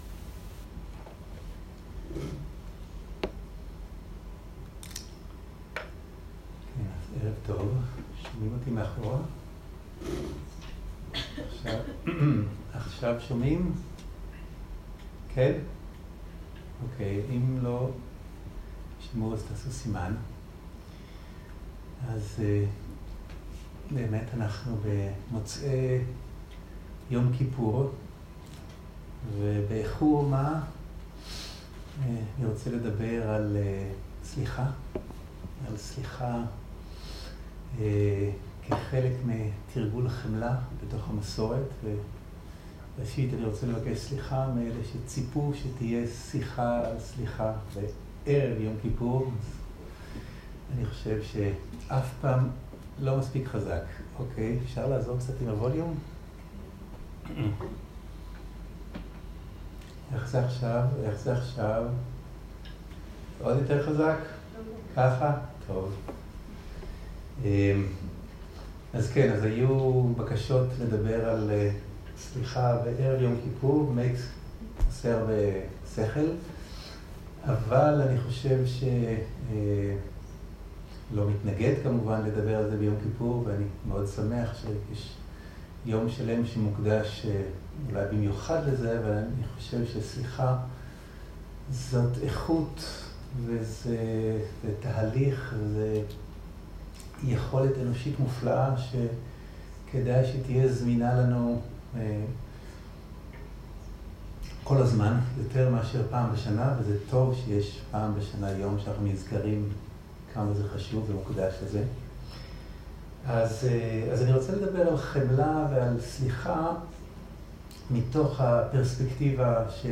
סוג ההקלטה: שיחות דהרמה
איכות ההקלטה: איכות גבוהה